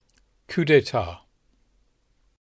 A coup d'état (/ˌkdˈtɑː/